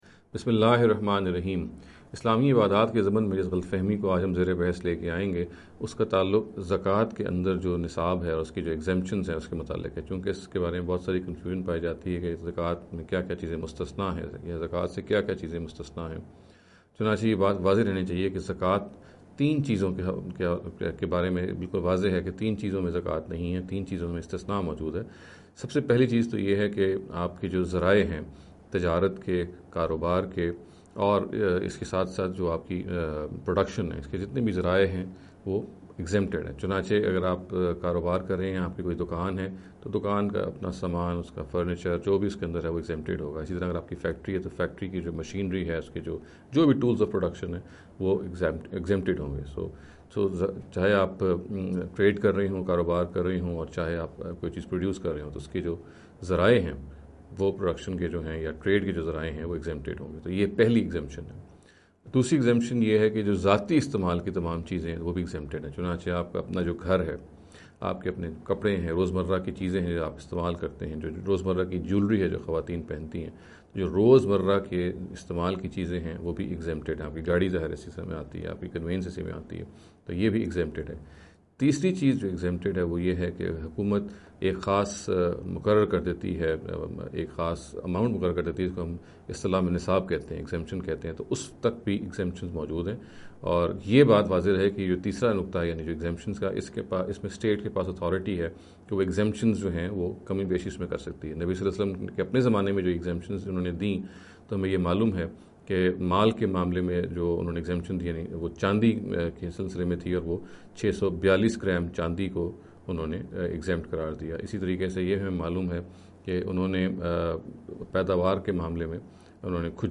This lecture series will deal with some misconception regarding the Islamic Worship Ritual. In every lecture he will be dealing with a question in a short and very concise manner.